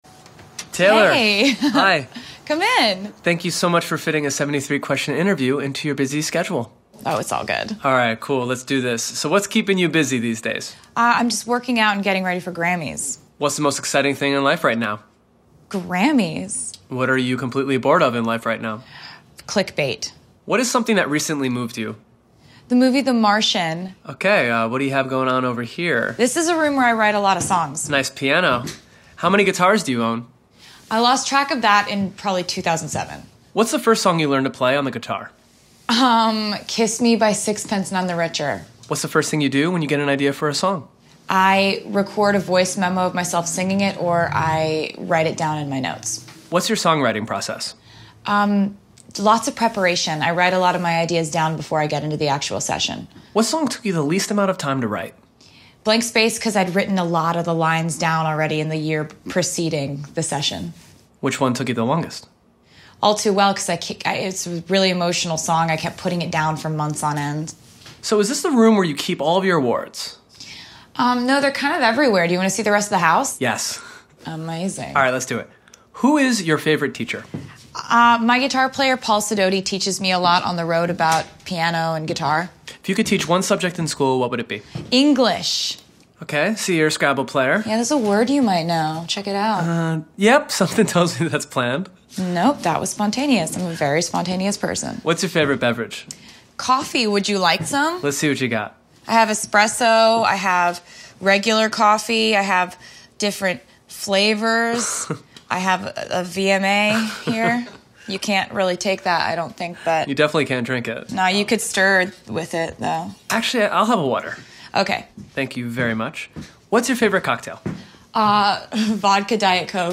PART 1 of taylor swift answering 73 questions on vogue in 2016